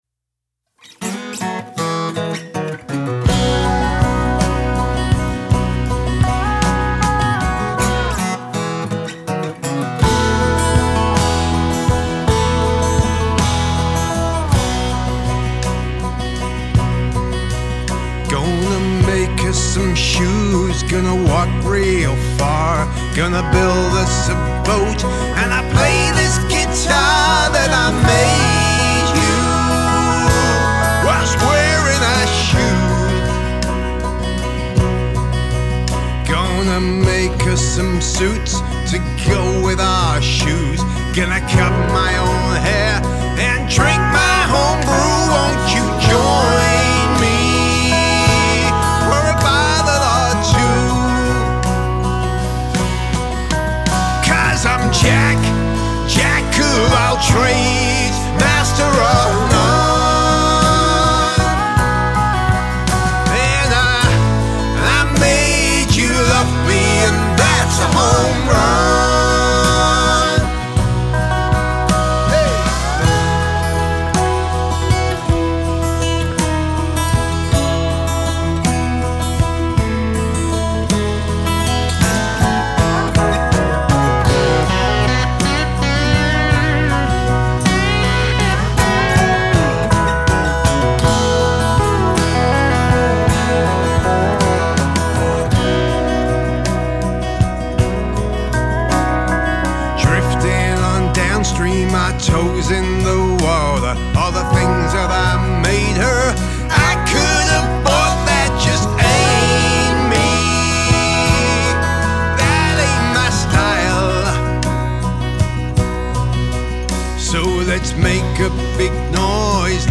Male Vocal, Guitar, Pedal Steel Guitar, Bass Guitar, Drums